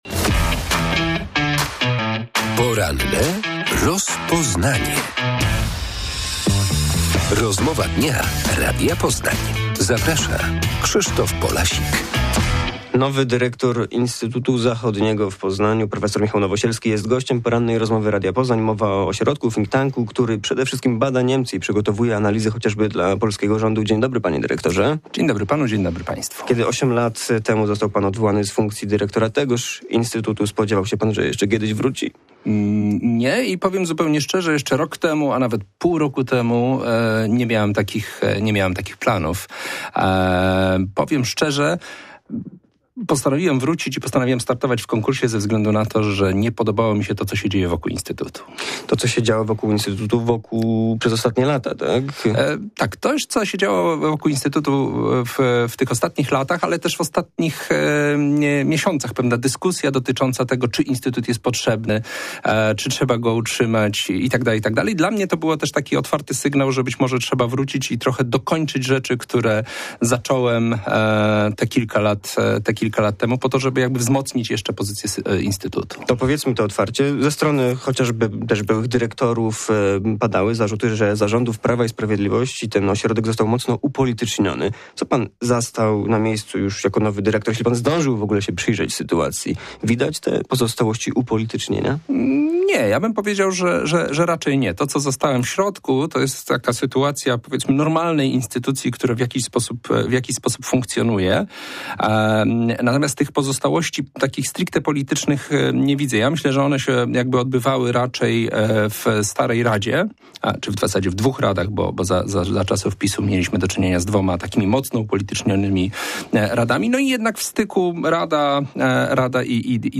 odpowiada na pytania